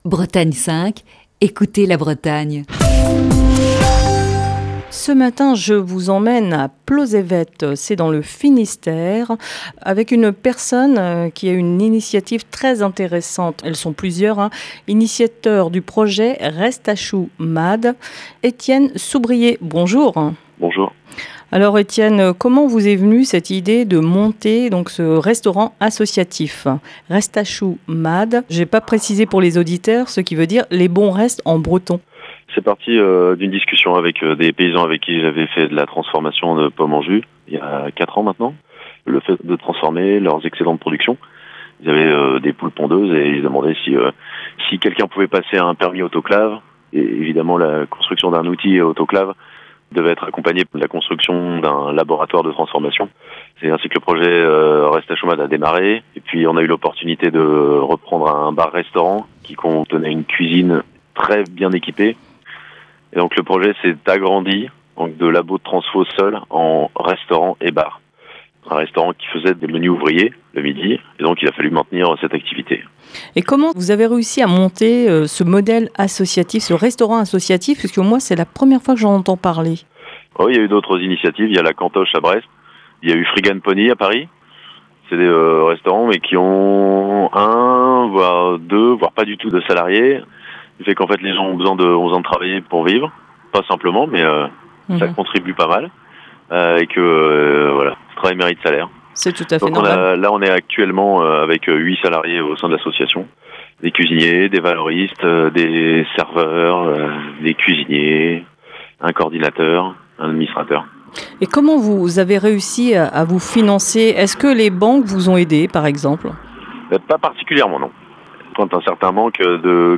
Ce lundi dans Le Coup de fil du matin